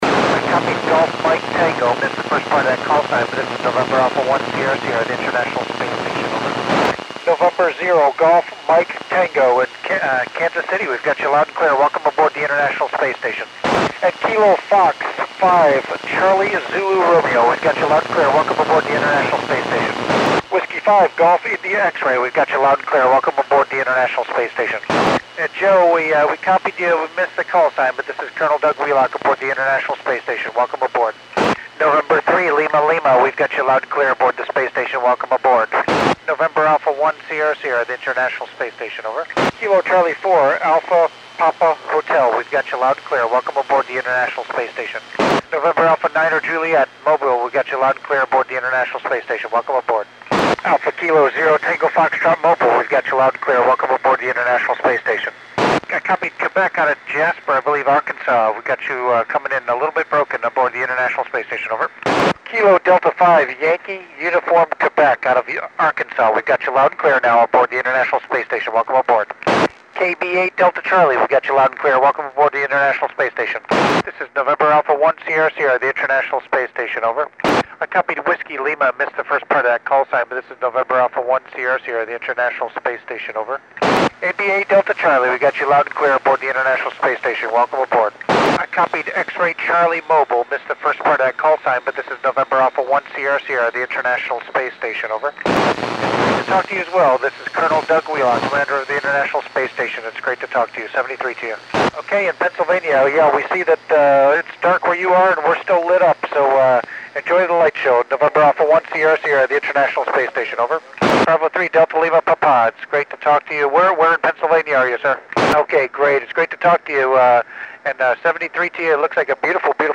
Col. Doug Wheelock (NA1SS) wks U.S. and Canadian stations